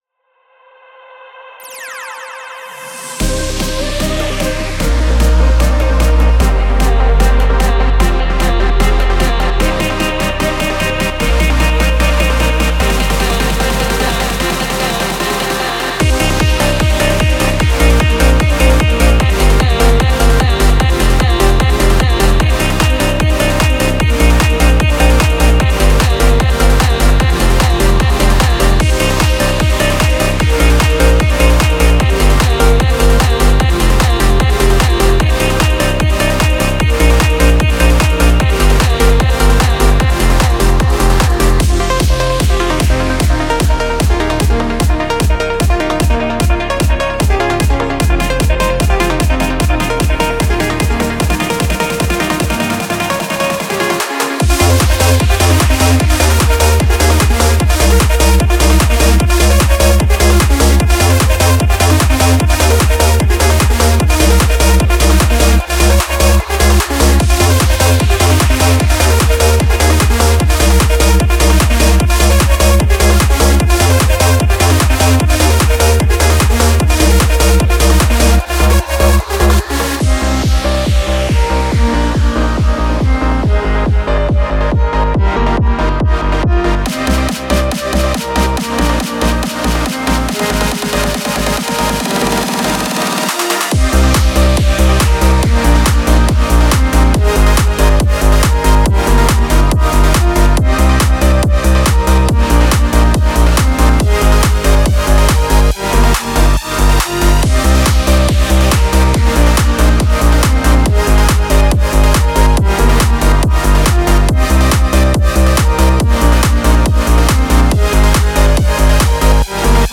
Type: Samples
Melodic Techno Techno
• 10 Gated Vocal Loops